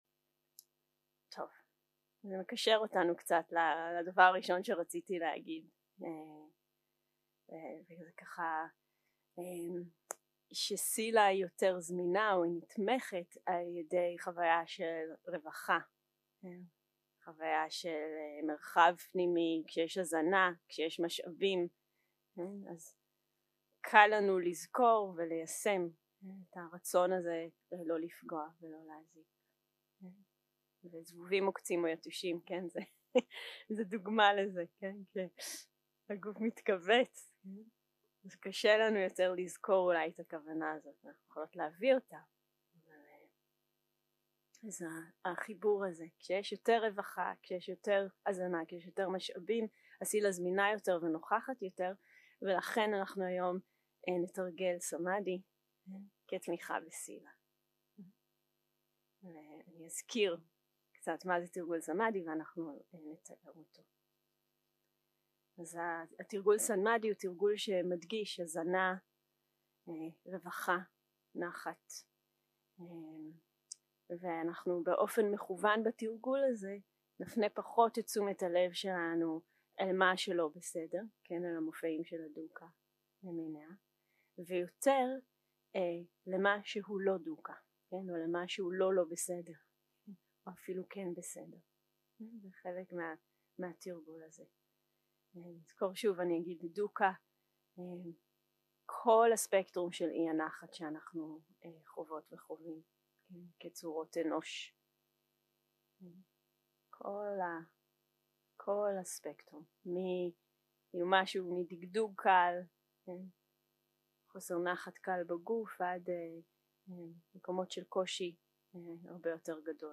יום 3 - הקלטה 5 - בוקר - הנחיות למדיטציה - תרגול הזנה ומשאוב 0:00 0:00 סוג ההקלטה: Dharma type: Guided meditation שפת ההקלטה: Dharma talk language: Hebrew